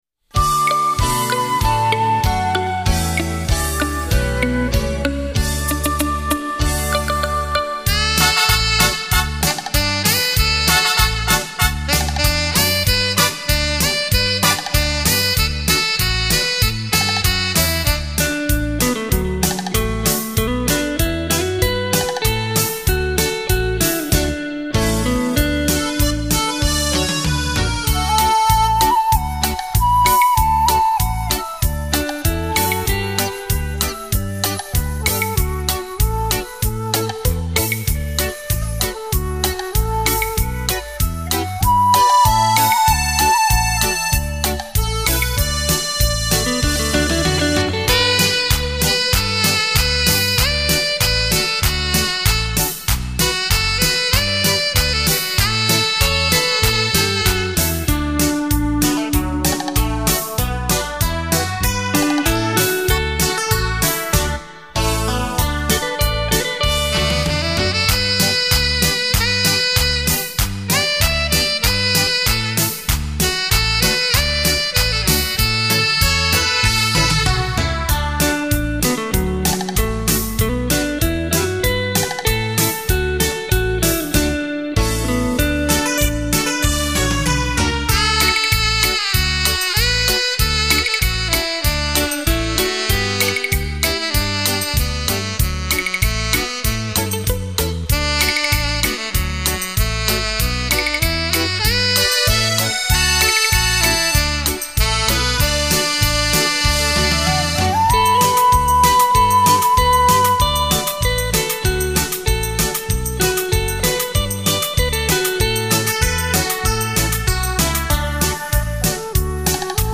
样本格式    : 44.100 Hz; 16 Bit; 立体声